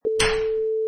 Clips: Door Latch 1
Old school door latch being squeezed
Product Info: 48k 24bit Stereo
Category: Doors / Doors General
Try preview above (pink tone added for copyright).
Door_Latch_1.mp3